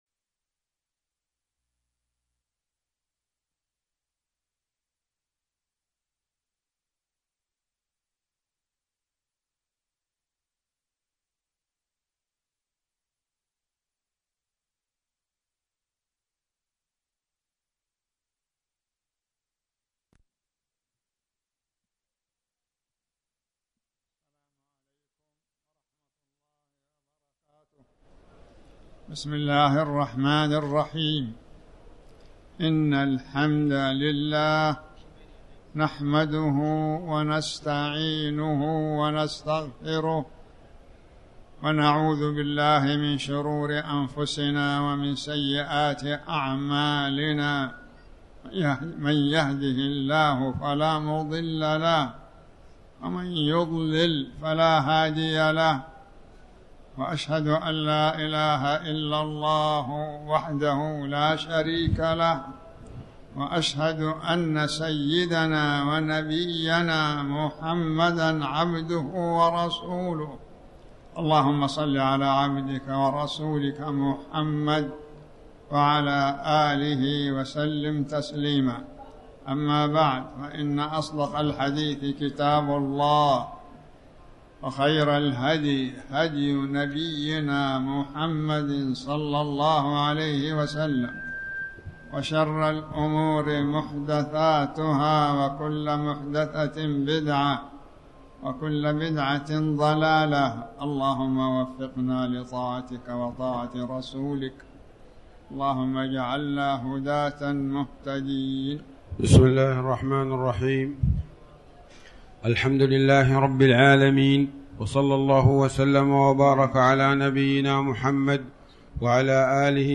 تاريخ النشر ٣ ربيع الأول ١٤٤٠ هـ المكان: المسجد الحرام الشيخ